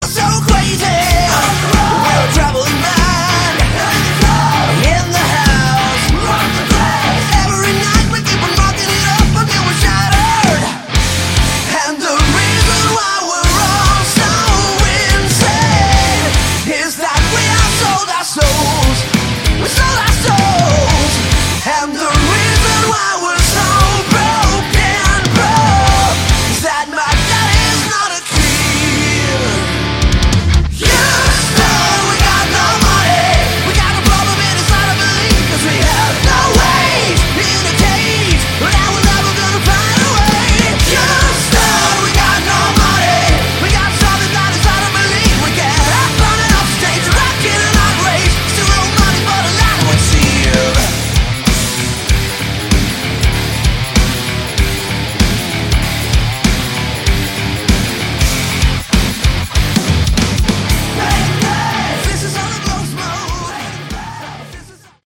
Category: Hard Rock
vocals
bass
guitars
drums